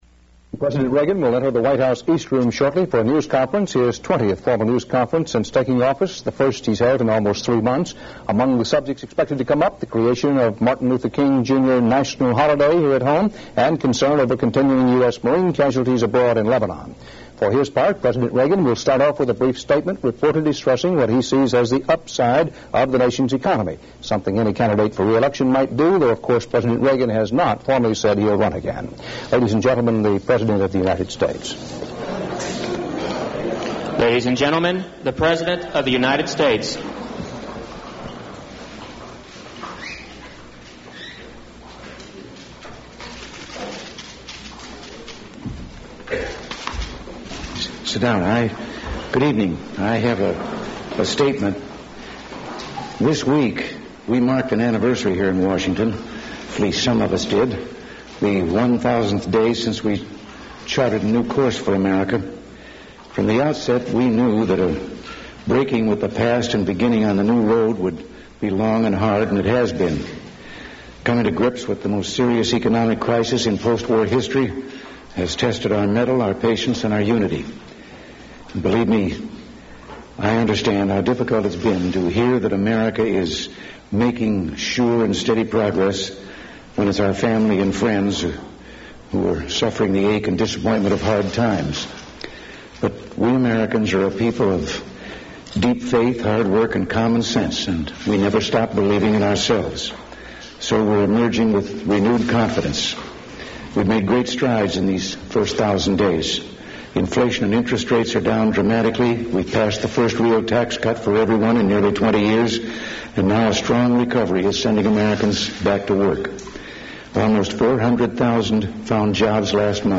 U.S. President Ronald Reagan's twentieth presidential press conference